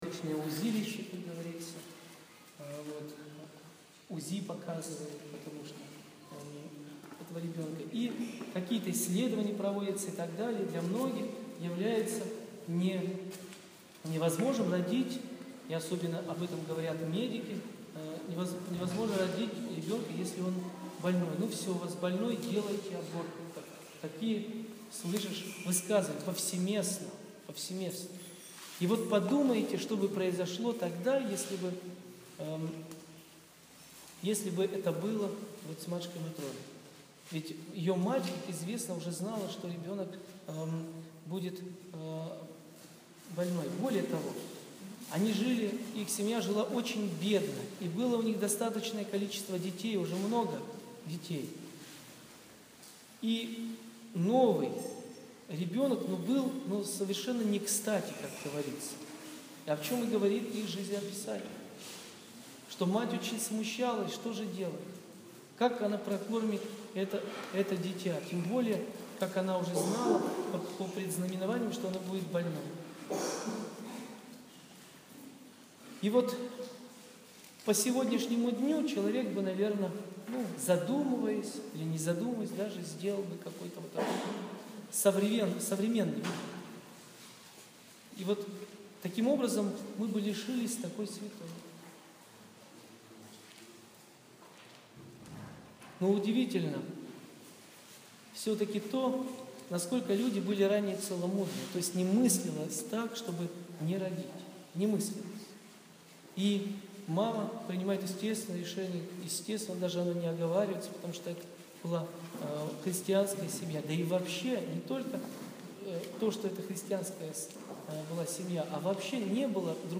Проповедь после молебна святой Матроне Московской